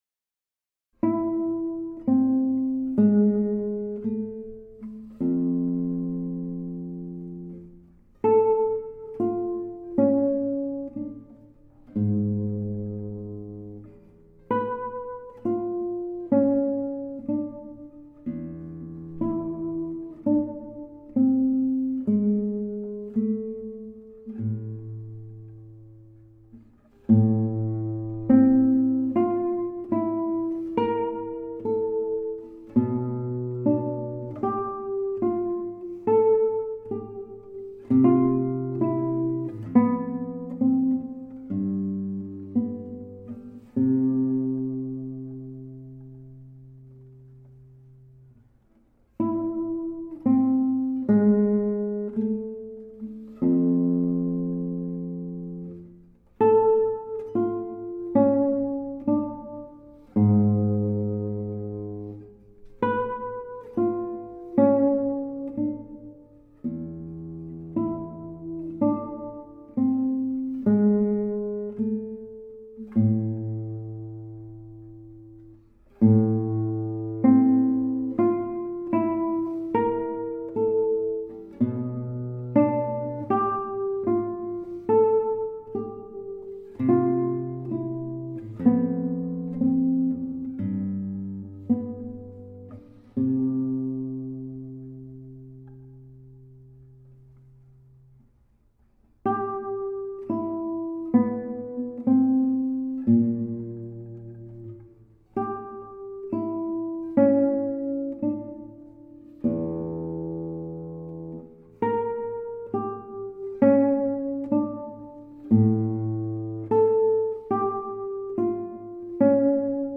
Guitarra Clásica